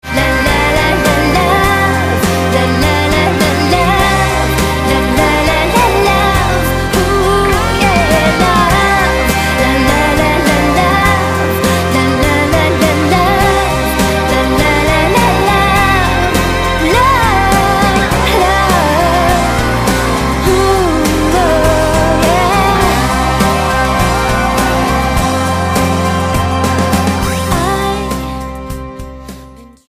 STYLE: Pop
engaging  jangling guitars